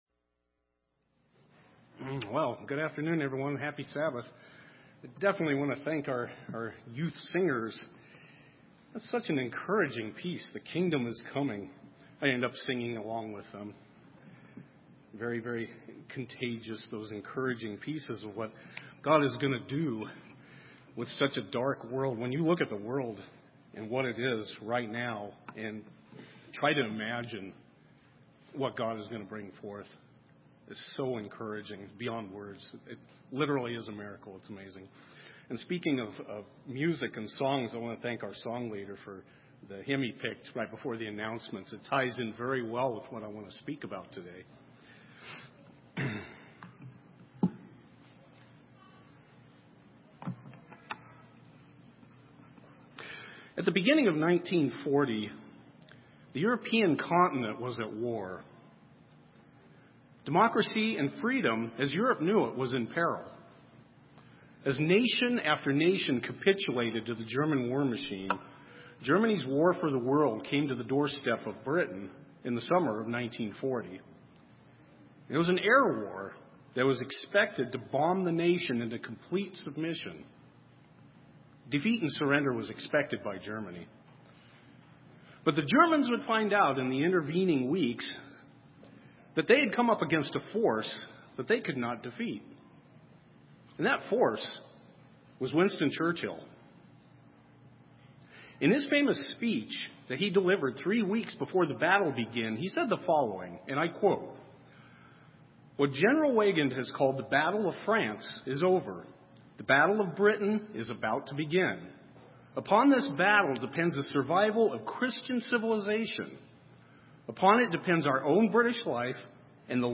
In this sermon we explore the topic of zeal and the important role it plays in the life of a true Christian.